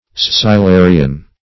Search Result for " scyllarian" : The Collaborative International Dictionary of English v.0.48: Scyllarian \Scyl*la"ri*an\, n. (Zool.)